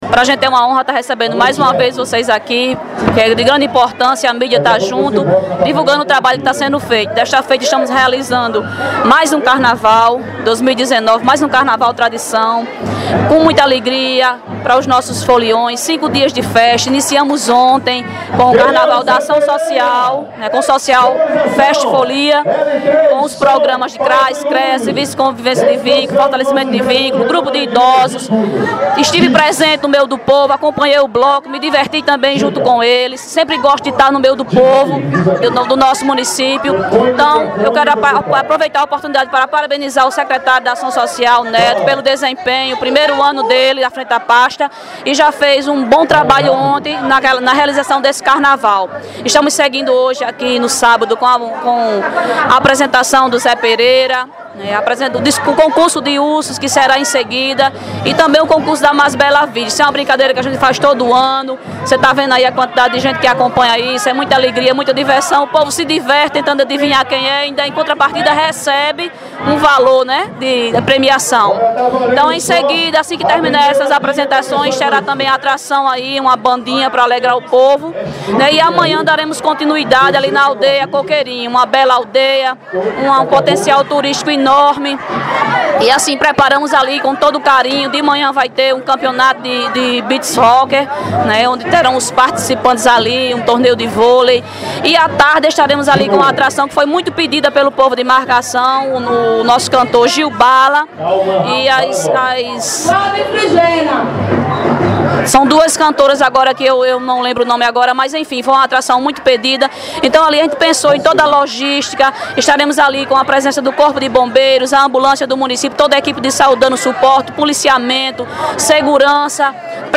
A prefeita Eliselma Oliveira (Lili), concedeu ao PBVale uma entrevista exclusiva falando sobre os festejos carnavalescos, ações administrativas e política.
Prefeitta-Lili-Carnaval-2019.mp3